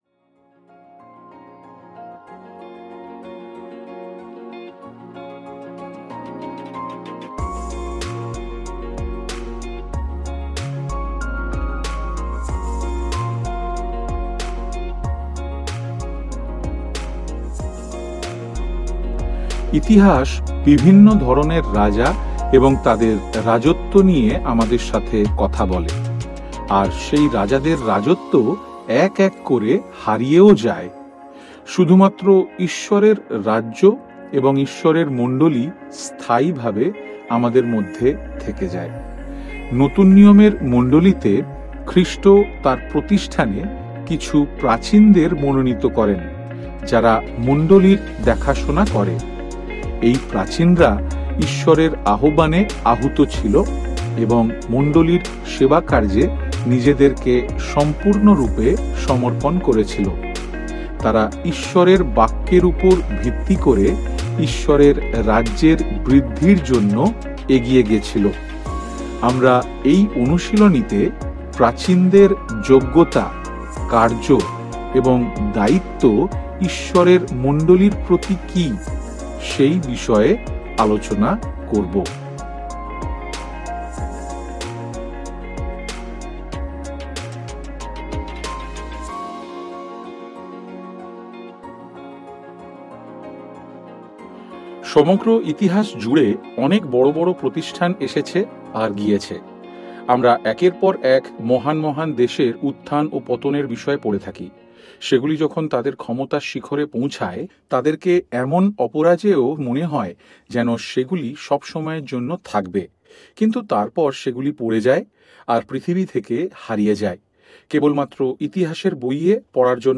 শুধুমাত্র এক রাজ্য আজও অব্যাহত—প্রভু যীশু খ্রীষ্টের মণ্ডলী। এই বক্তৃতায় আমরা বাইবেল ভিত্তিক সত্য আবিষ্কার করব যে যীশুর মণ্ডলী একটি রাজ্য—যার একজন রাজা আছেন এবং একটি শাসনব্যবস্থা আছে—যা খ্রীষ্ট নিজে নিয়োগ করেছেন।